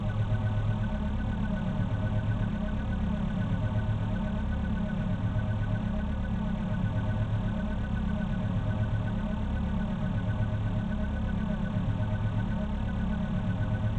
PortalNonMagical 1.wav